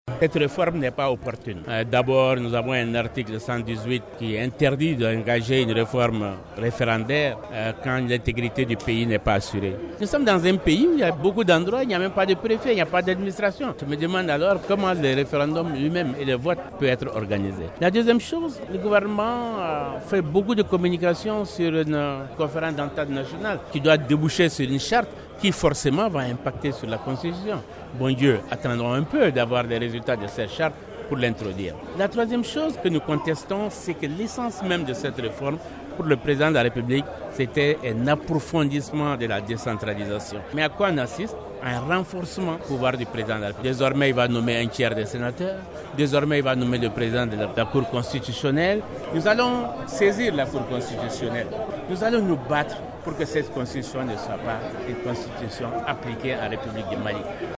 Soumaïla Cissé chef de fil de l’opposition :